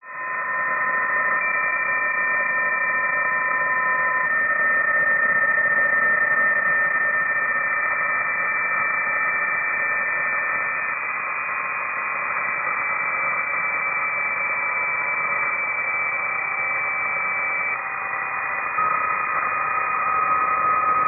Here are the WAV files he generated with Doppler changing at